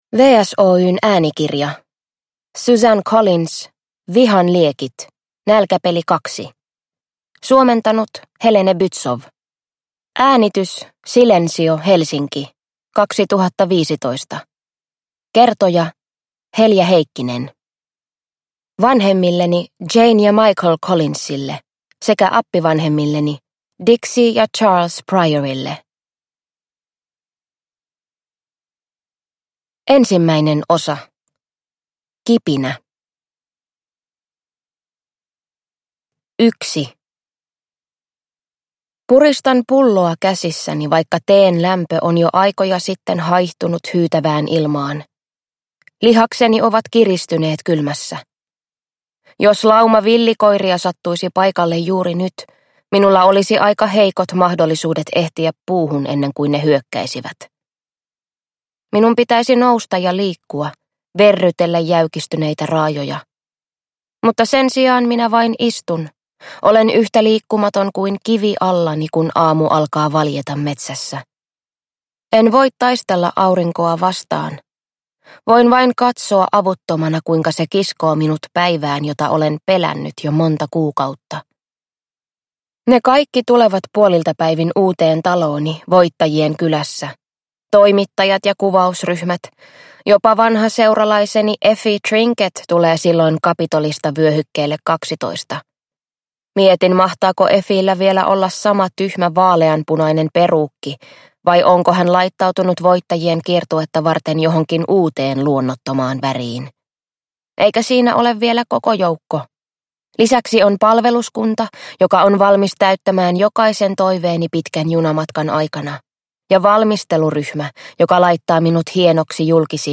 Nälkäpeli: Vihan liekit – Ljudbok
Uppläsare: